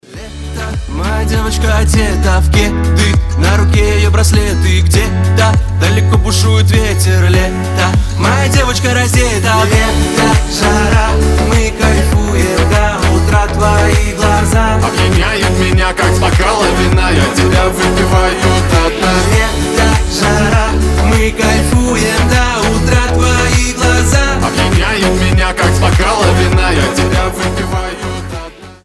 • Качество: 320, Stereo
гитара
позитивные
легкие